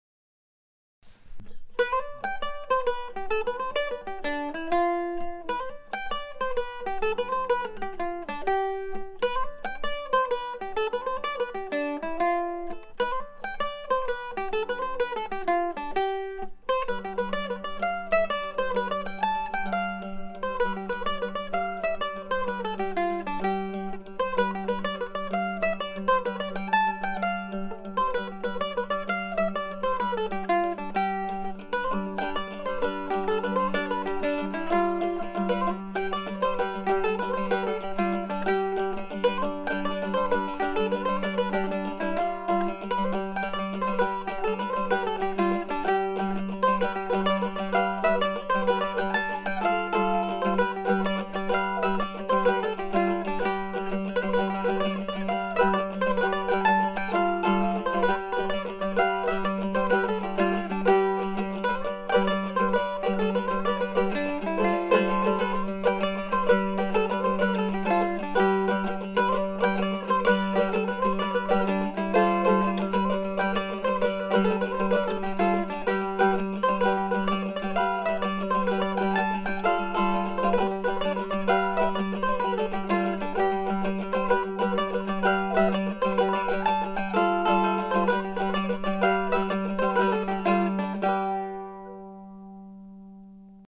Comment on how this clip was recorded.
Recorded quickly and simply with just two mandolin tracks using the built-in mics of the DP-008.